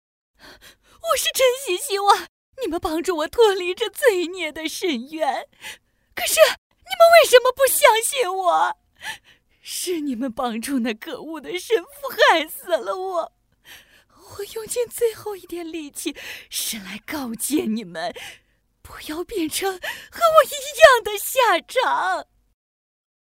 • 40专业女声7
角色扮演【凄惨女主】